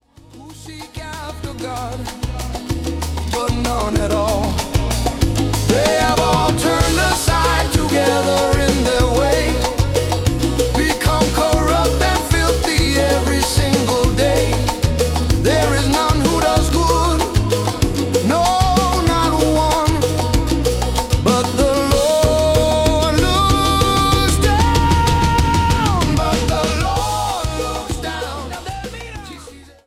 Latin Rock Worship